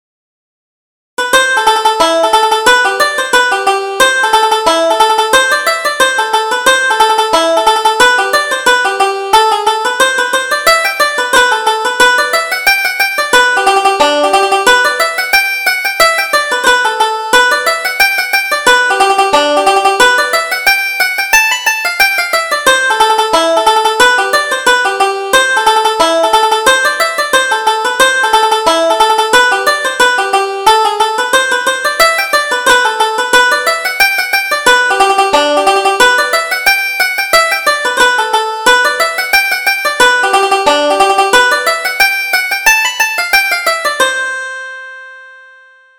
Reel: Mary Grace